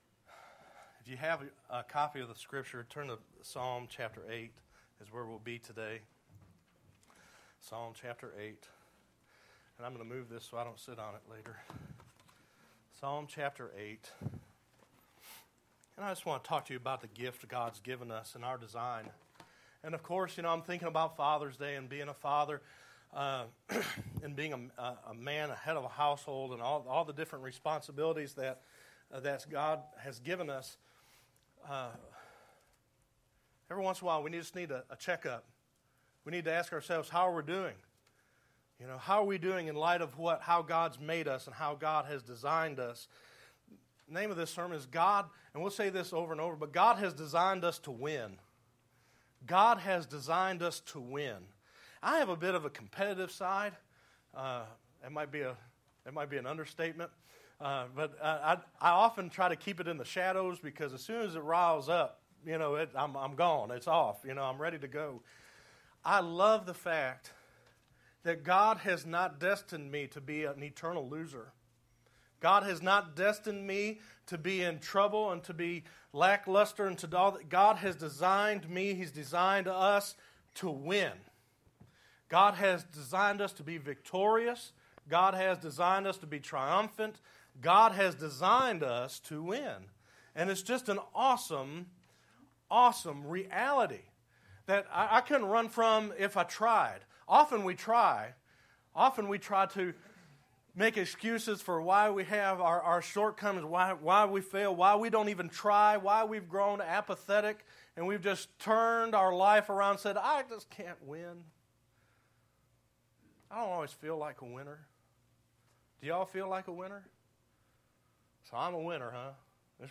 6_17_12_Sermon.mp3